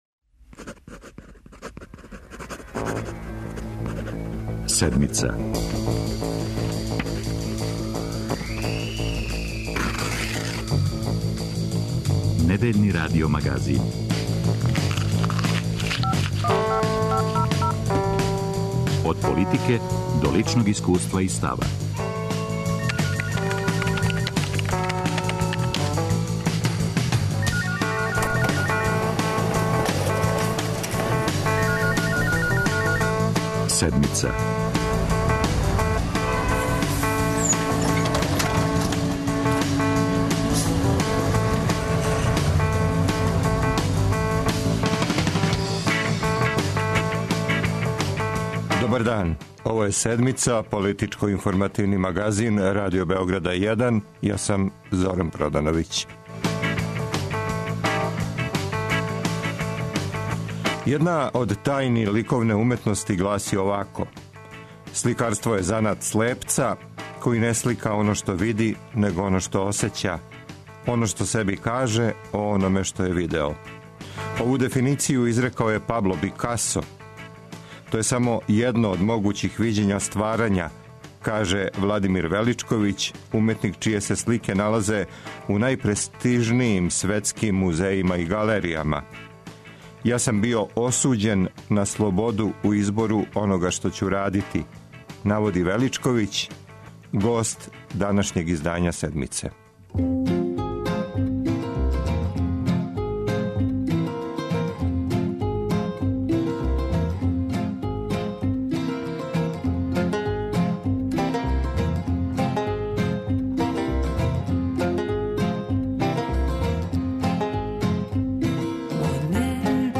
Он је хуманиста који указује на разарајуће опасности данашњег света, а својим сликама манифестује отпор и опирање катаклизми. Владимир Величковић, академик, светски признати уметник, српски сликар који живи и ради у Паризу, гост је овонедељног издања магазина Седмица.